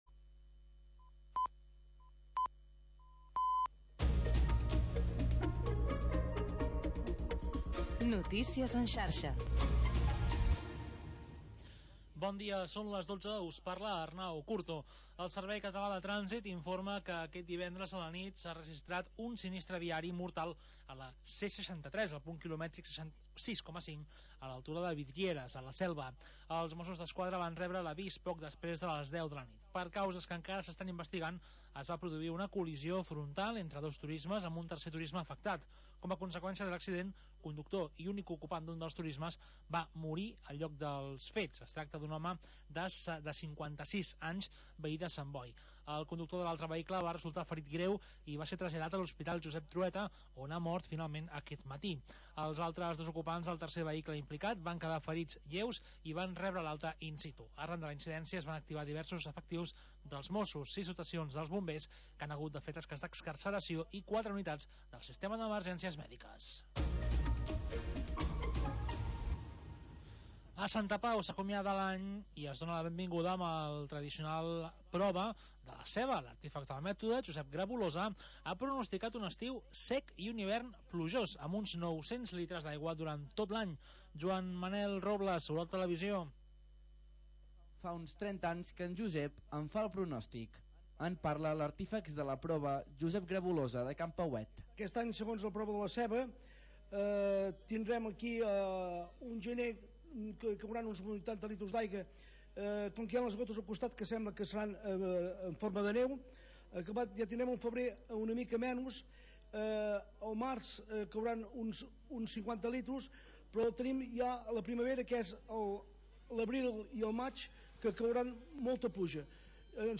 Programa sardanista